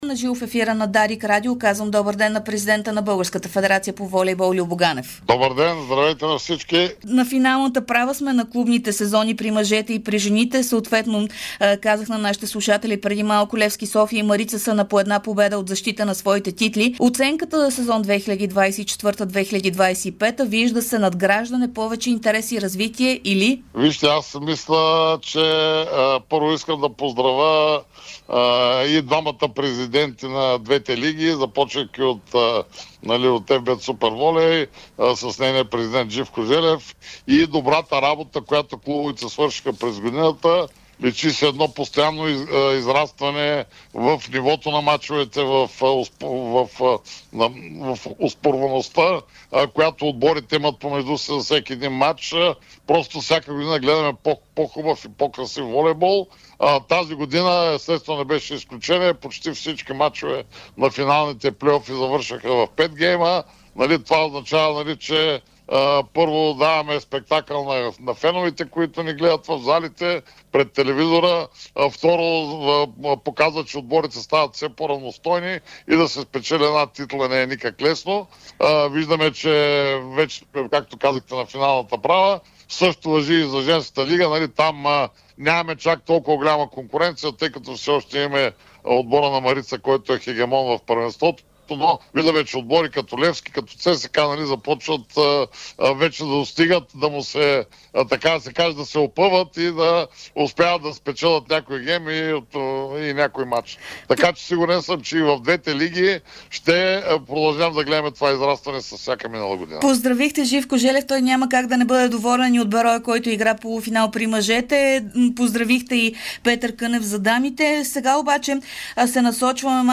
Президентът на Българска федерация по волейбол Любомир Ганев говори ексклузивно пред Дарик радио и dsport за отминаващия сезон в родните шампионати при мъжете и жените, като разкри интересни новини около предстоящите мачове на мъжкия и женския национален отбор, както и тези в различните гарнитури при момчетата и момичетата. Ганев разкри в ефира на Дарик радио, че България ще има „А“ и „Б“ национален отбор при мъжете.